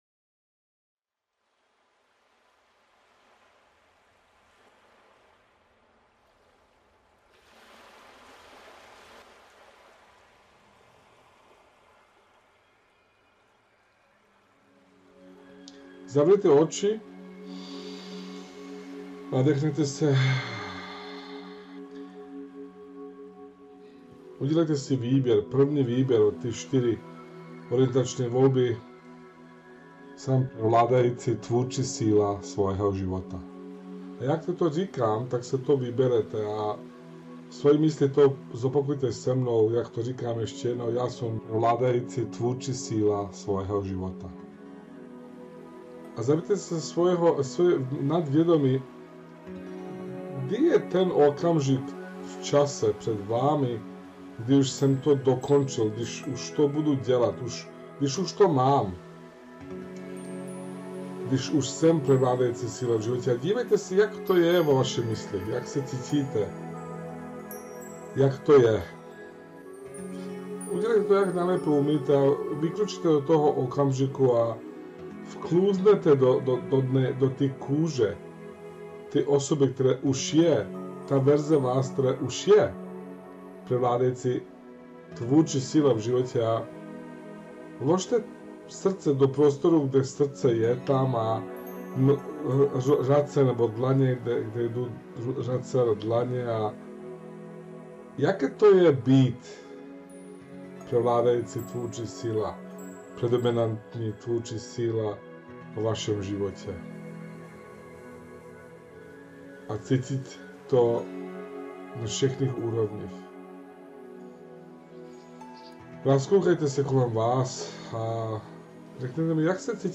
Jedná se o vedenou meditaci, která vás provede celým procesem.